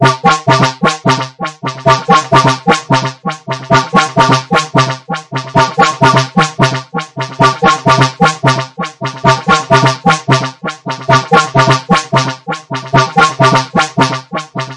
标签： 电子
声道立体声